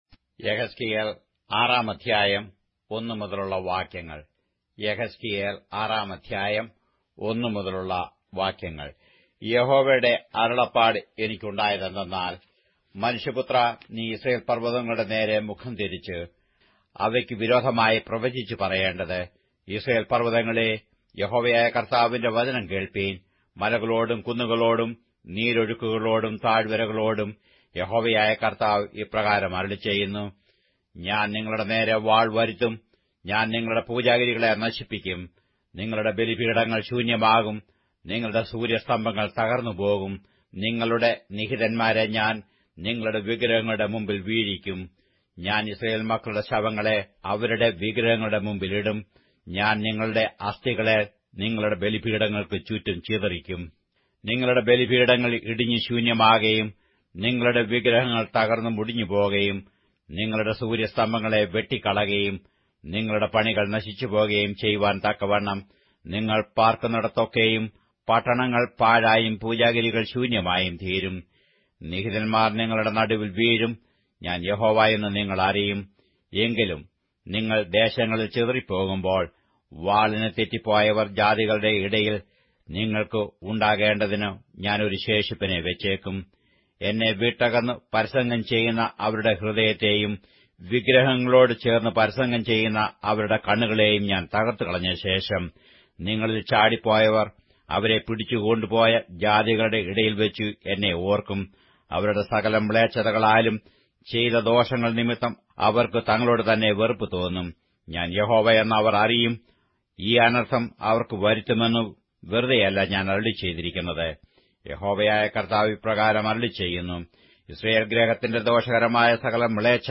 Malayalam Audio Bible - Ezekiel 23 in Alep bible version